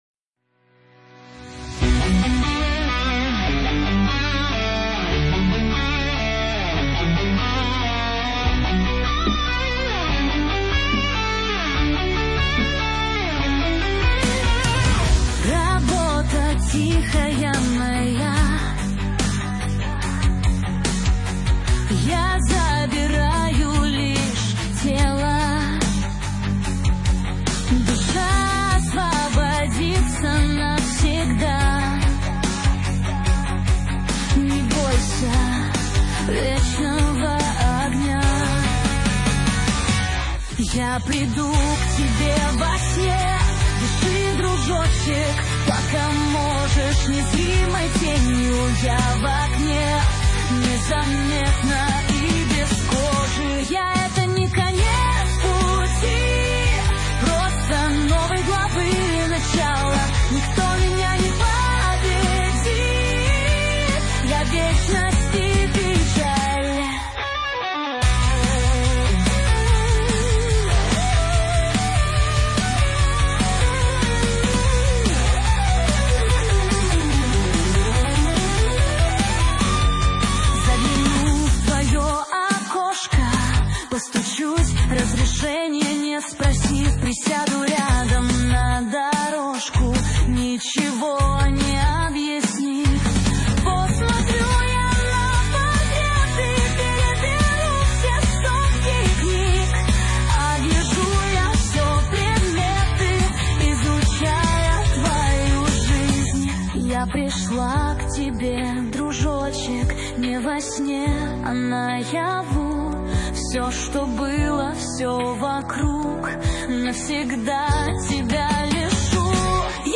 Avtorskaja_pesnja_Pesn_Smerti.mp3